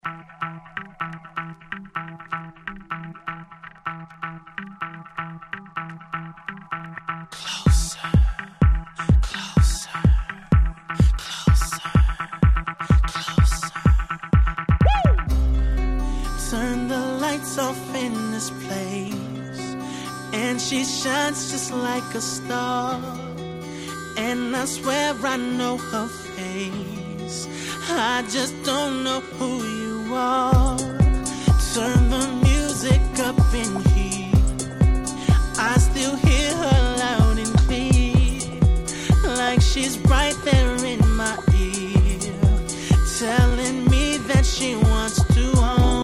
(試聴45秒)